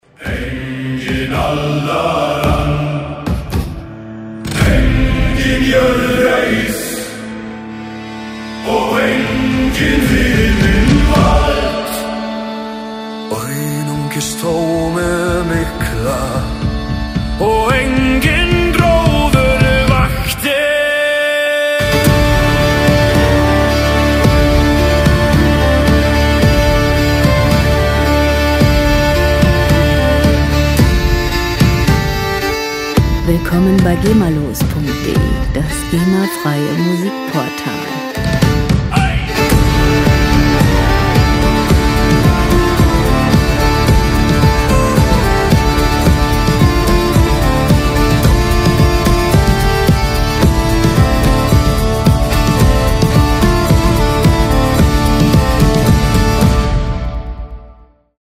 World Music GEMA-frei
Musikstil: Nordic Folk
Tempo: 110 bpm
Tonart: D-Moll
Charakter: archaisch, monumental